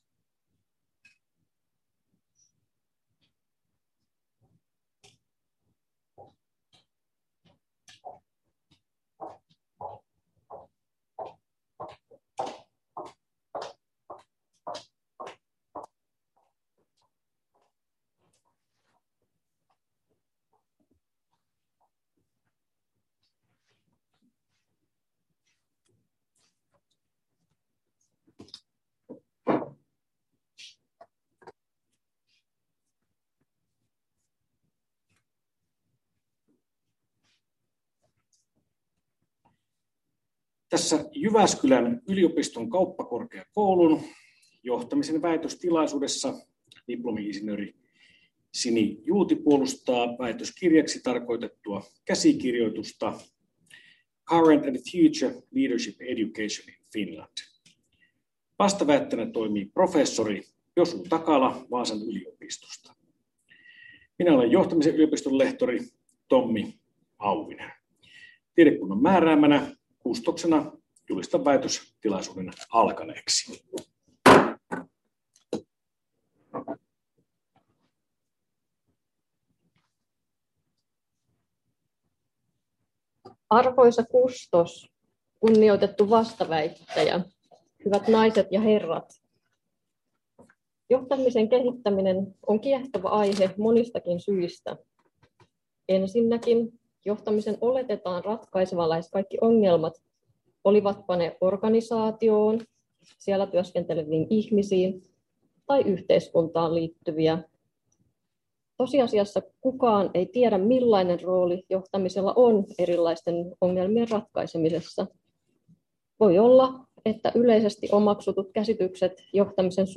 Väitöstilaisuus 26.3.2021